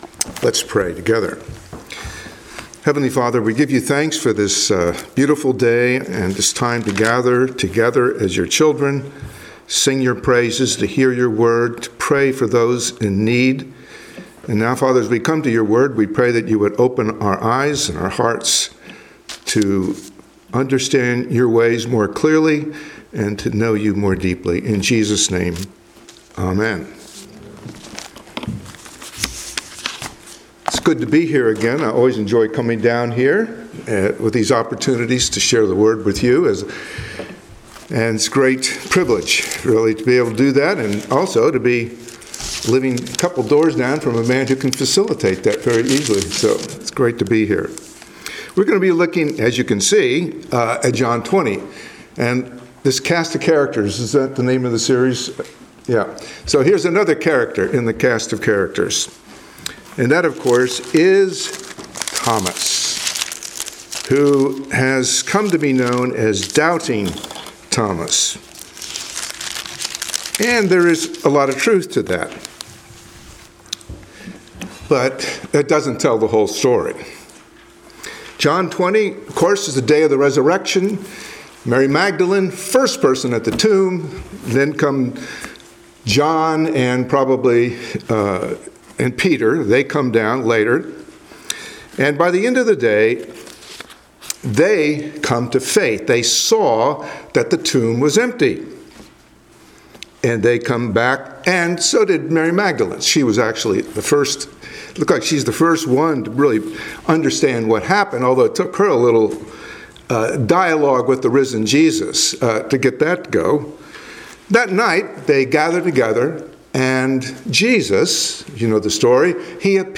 Passage: John 20:24-29 Service Type: Sunday Morning Worship